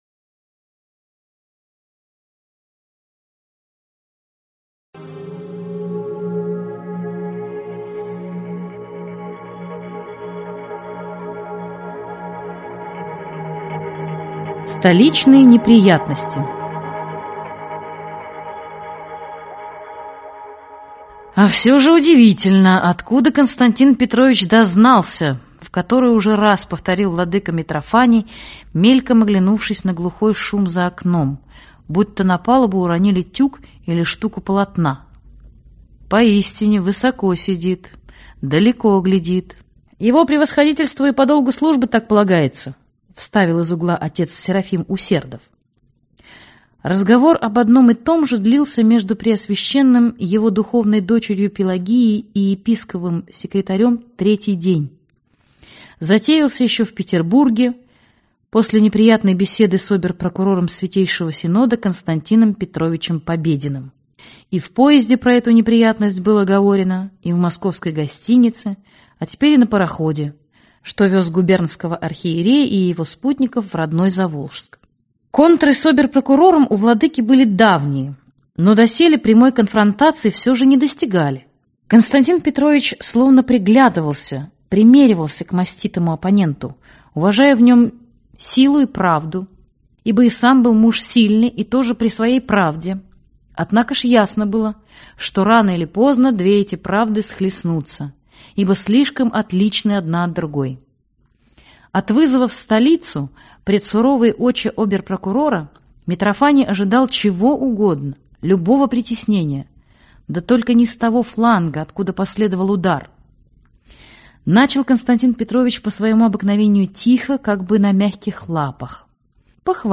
Аудиокнига Пелагия и красный петух - купить, скачать и слушать онлайн | КнигоПоиск